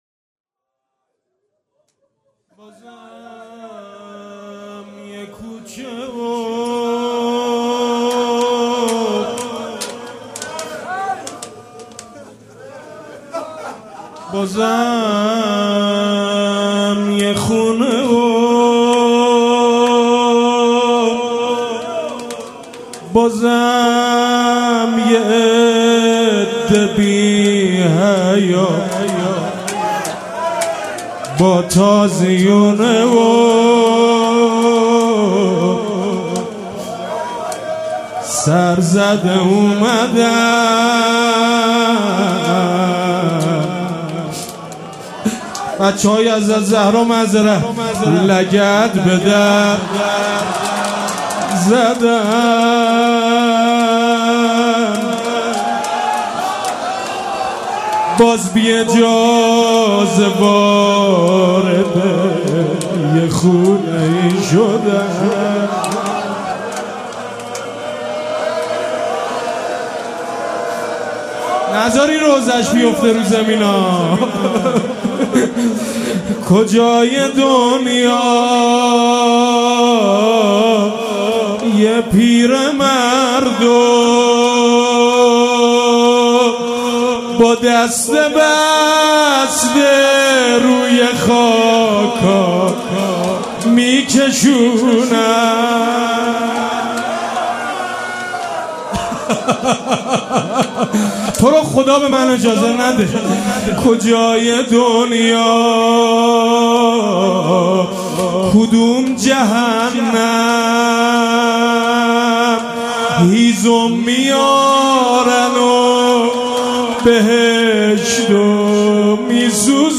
مراسم عزاداری شهادت امام صادق علیه السّلام
حسینیه ریحانة‌الحسین(س)
روضه